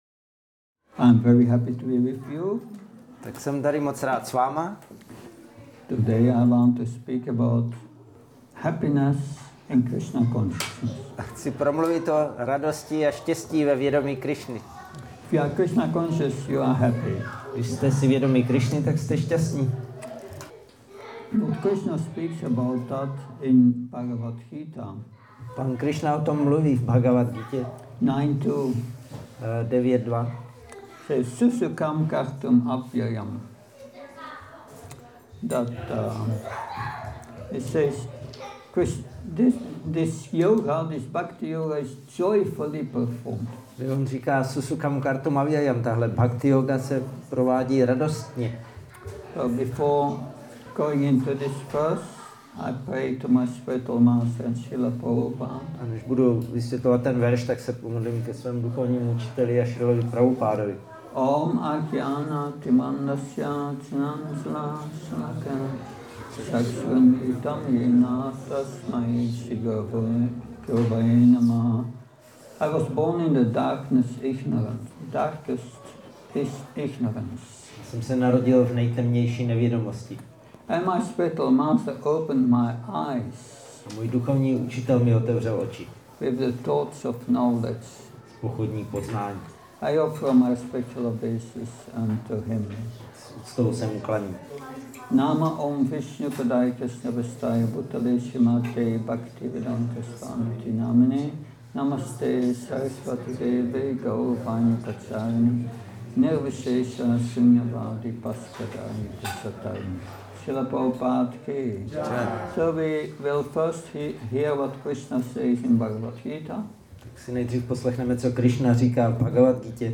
Přednáška BG-9.2 – Klub kpt. Nema